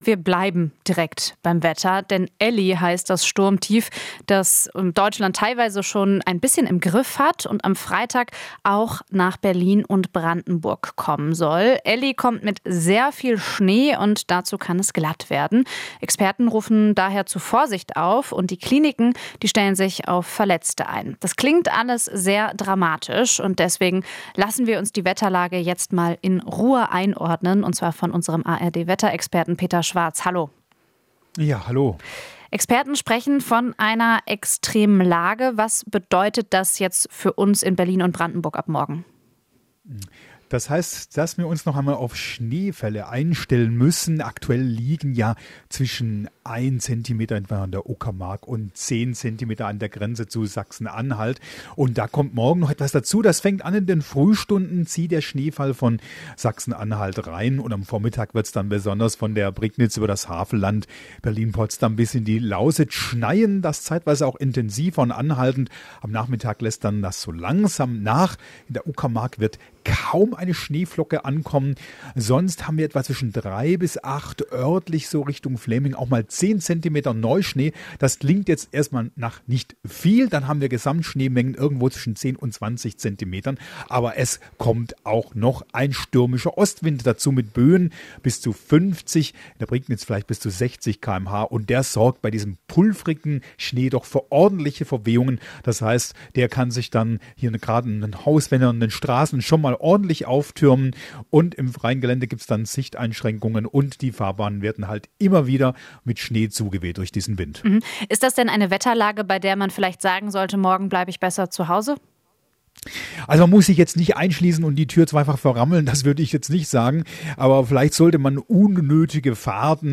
Alles was wichtig ist in der Hauptstadtregion - in Interviews, Berichten und Reportagen.